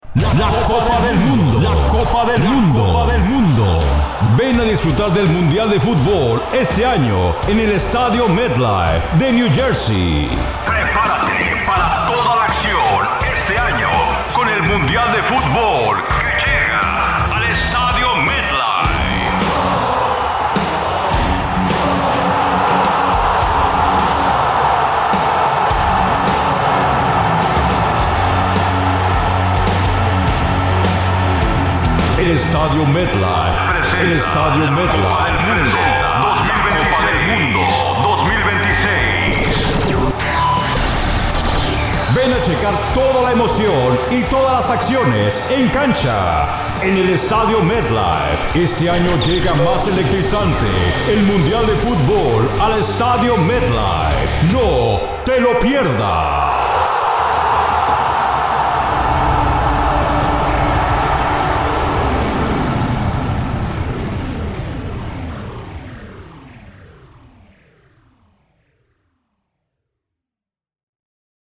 My voice could be varied according to the content and client's needs. It would be warm, natural, informative or serious, authoritative.